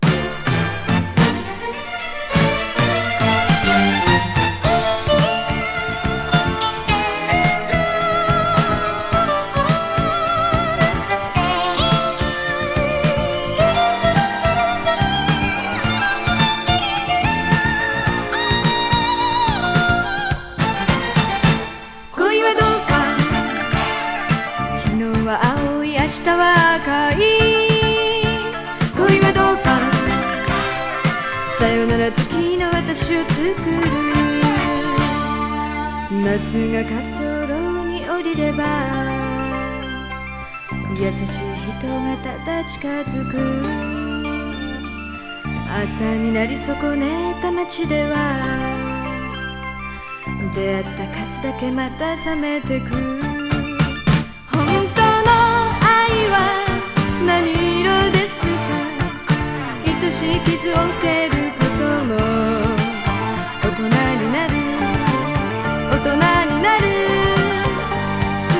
デビュー曲にしては渋い曲調のグルーヴィ歌謡。